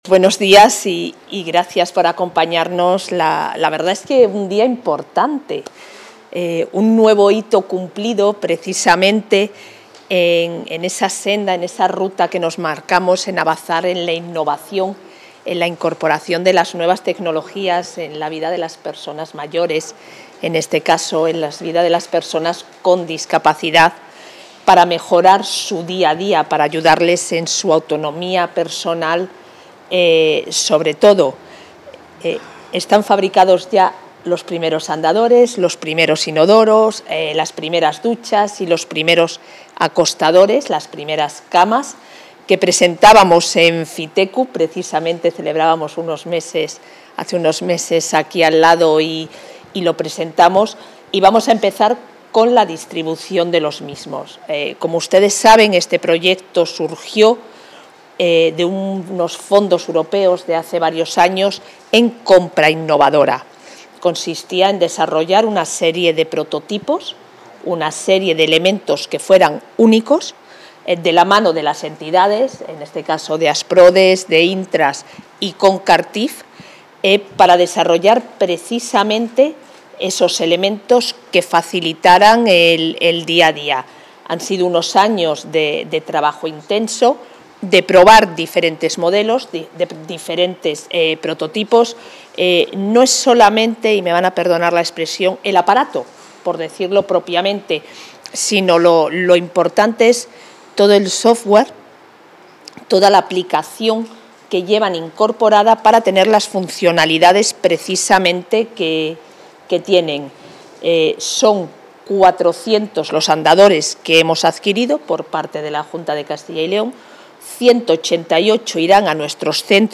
Intervención de la vicepresidenta de la Junta.
La Consejería de Familia e Igualdad de Oportunidades ha adquirido estos productos con una inversión de 5,4 millones de euros, que distribuirá entre sus centros residenciales de personas mayores y de personas con discapacidad, así como entre los usuarios de ‘A gusto en casa’. La vicepresidenta del Ejecutivo autonómico y también consejera del área ha recibido 404 andadores, 265 inodoros, 300 duchas y 285 acostadores en el centro tecnológico 'La Aldehuela' de Zamora, desde donde partirán hacia todas las provincias.